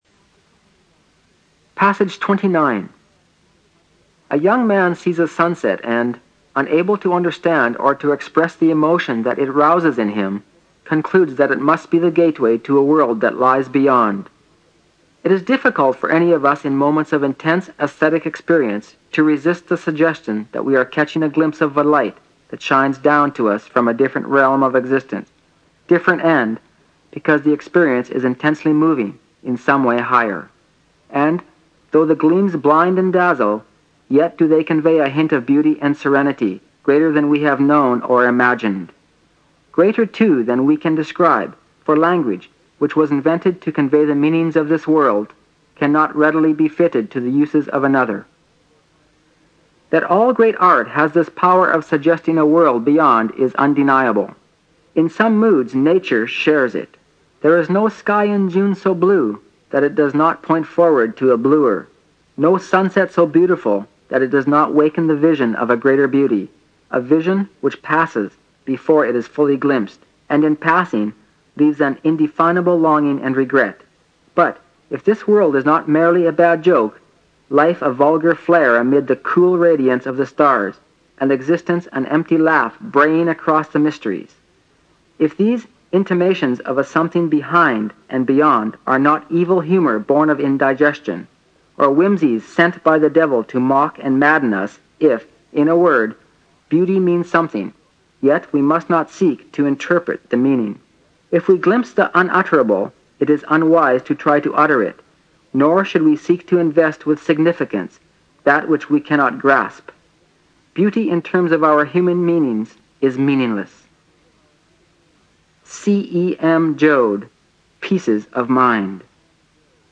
新概念英语85年上外美音版第四册 第29课 听力文件下载—在线英语听力室